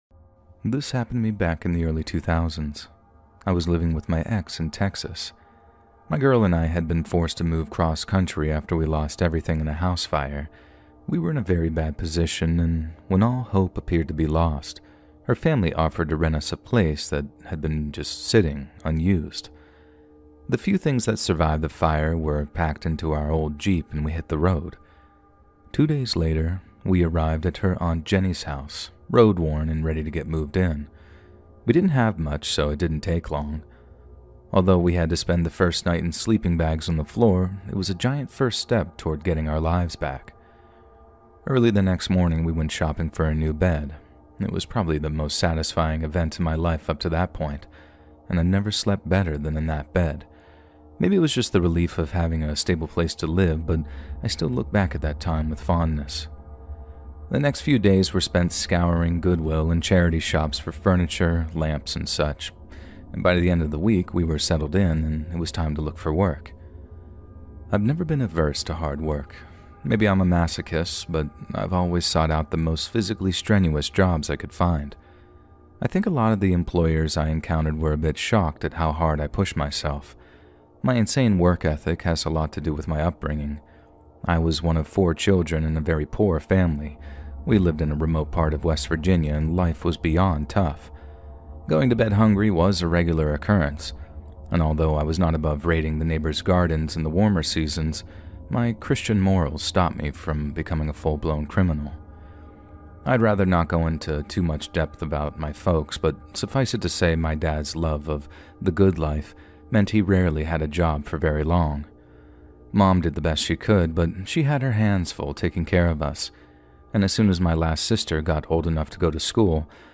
4 Diner/Sub Shop Horror Stories Behind the Counters